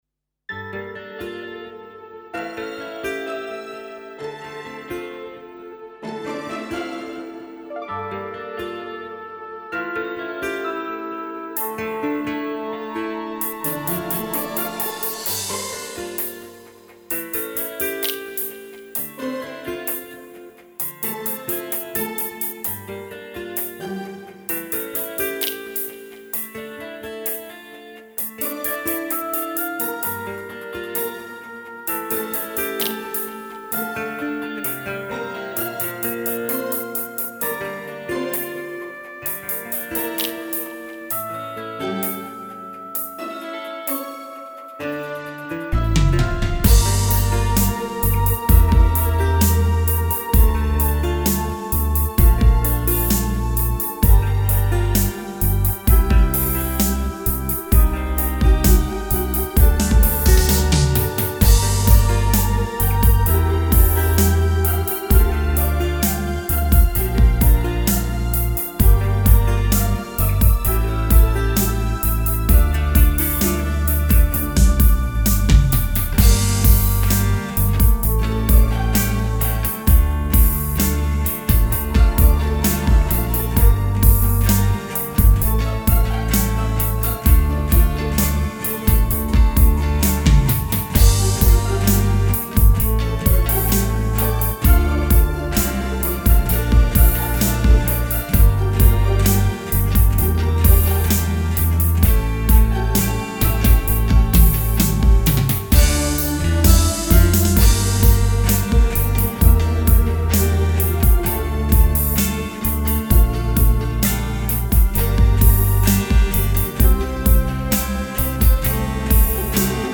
минусовка версия 241987